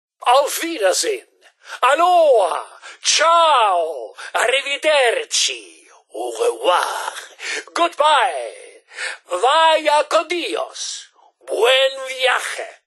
Fallout 76: Audiodialoge
Beschreibung Lizenz Diese Datei wurde in dem Video-Spiel Fallout 76 aufgenommen oder stammt von Webseiten, die erstellt und im Besitz von Bethesda Softworks sind, deren Urheberrecht von Bethesda Softworks beansprucht wird.